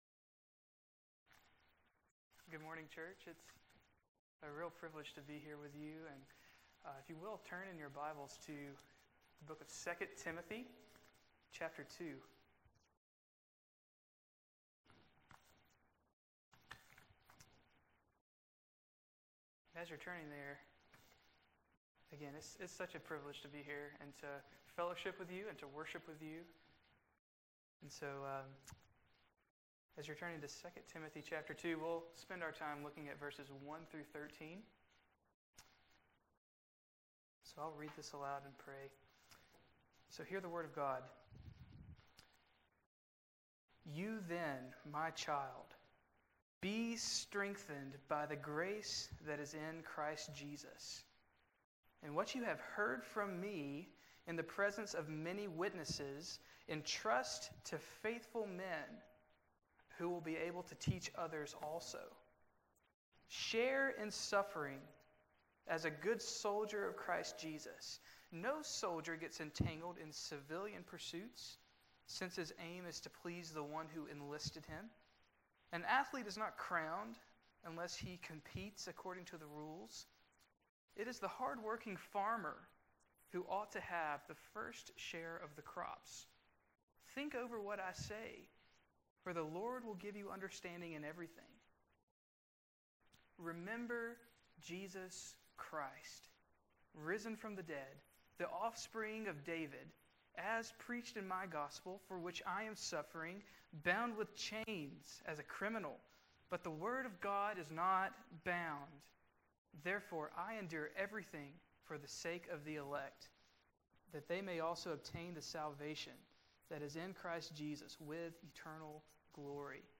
April 10, 2016 Morning Worship | Vine Street Baptist Church